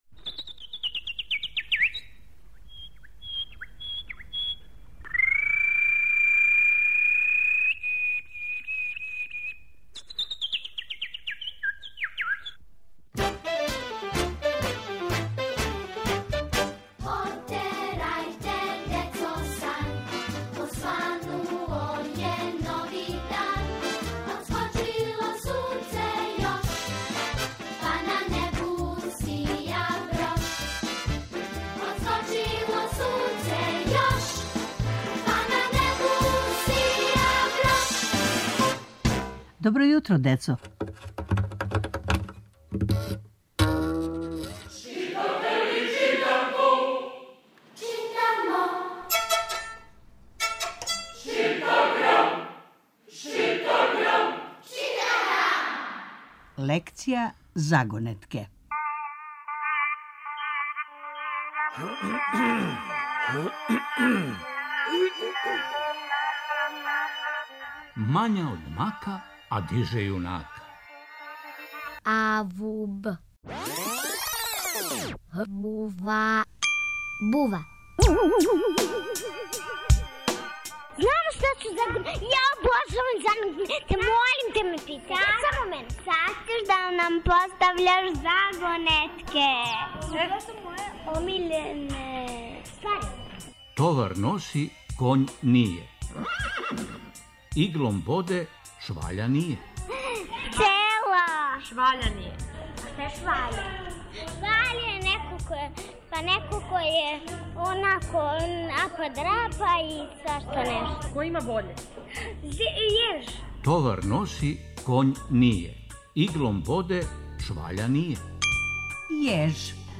Сваког понедељка у емисији Добро јутро, децо - ЧИТАГРАМ: Читанка за слушање. Ове недеље - први разред, лекција: Загонетка.